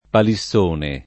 [ pali SS1 ne ]